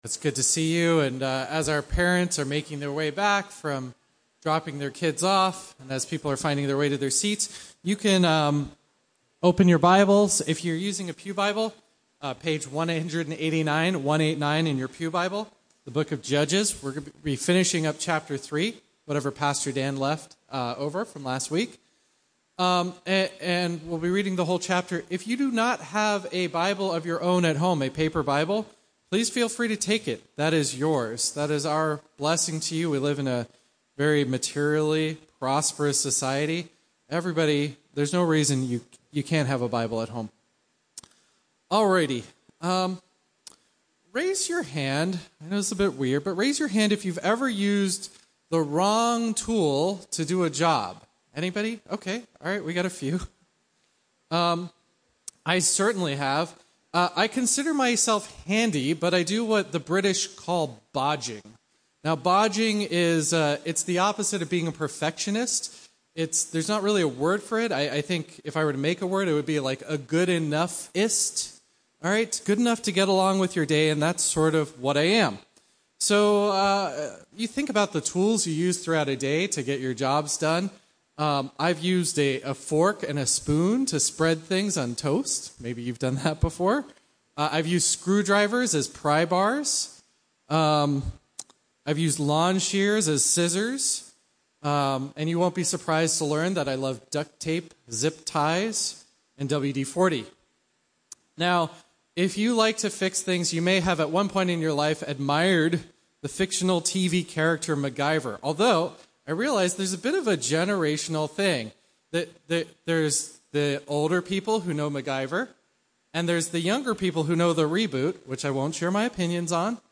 A message from the series "Judges."